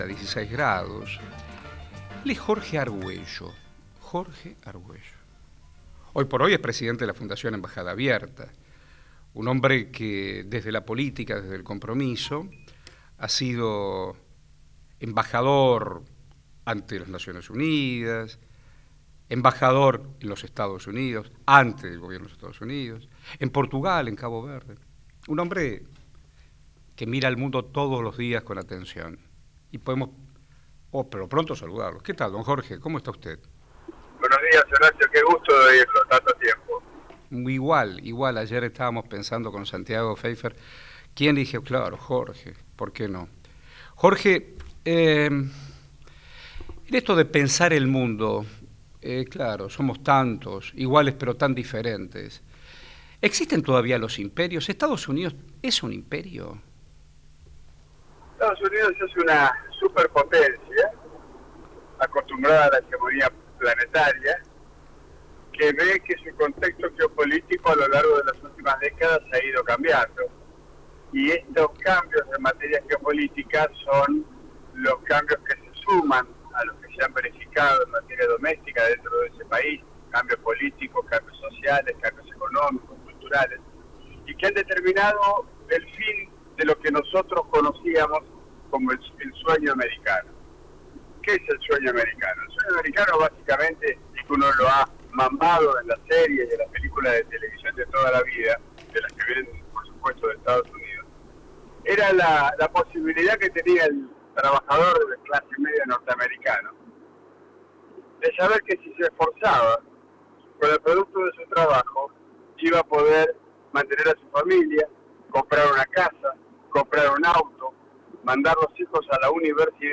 El ex embajador argentino en Estados Unidos, Jorge Arguello, analizó la victoria de Donald Trump en la elecciones presidenciales.